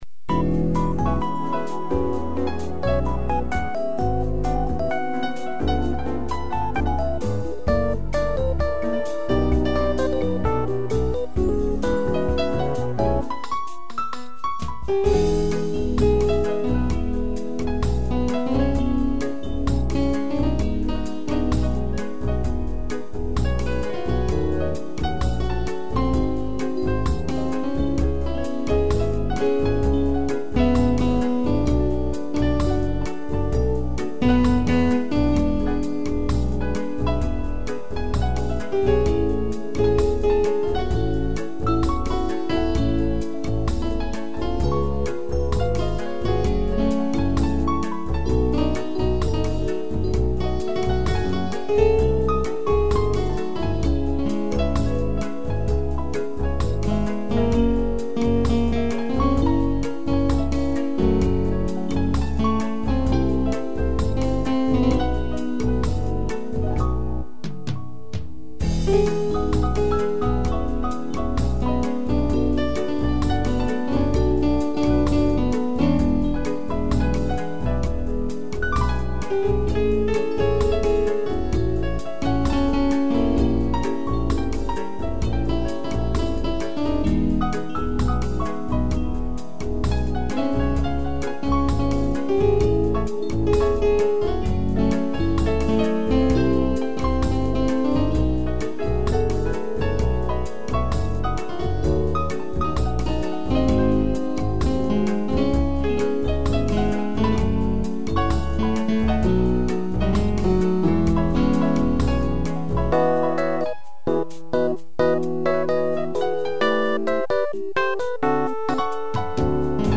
piano
(instrumental)